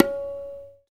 TABLA TAH -S.WAV